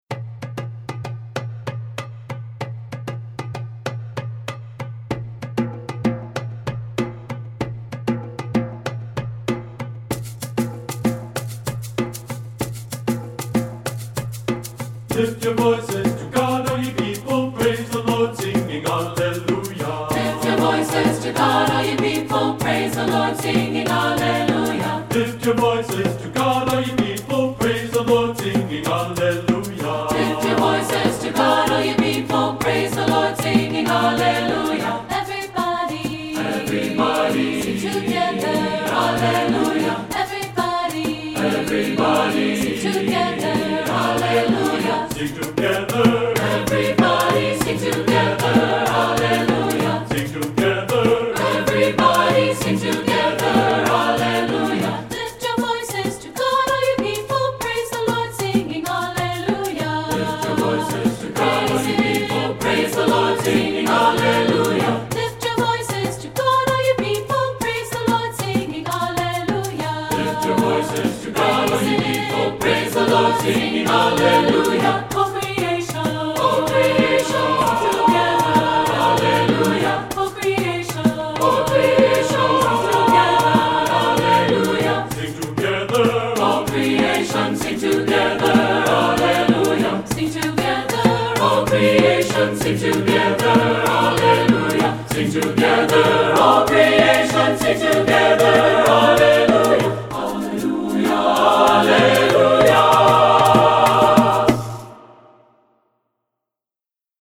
Voicing: SAB a cappella